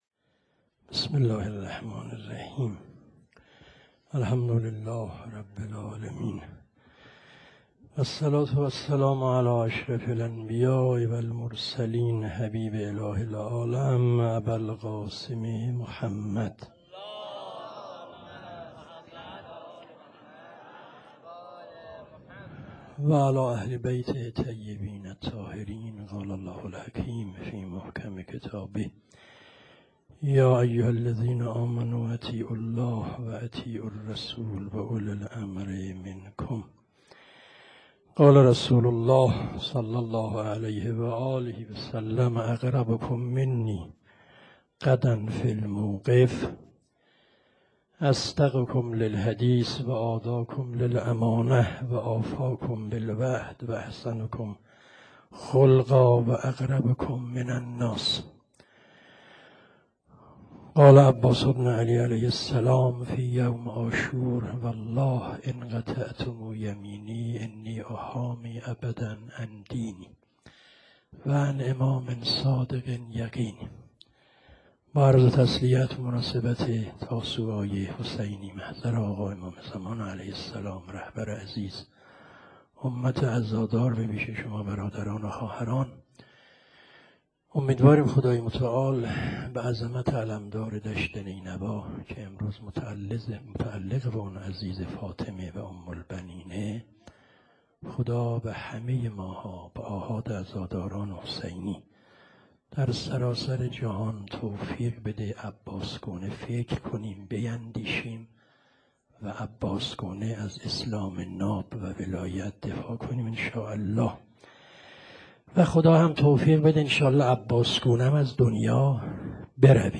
روز تاسوعا _ سخنرانی حاج آقا فلسفی.wma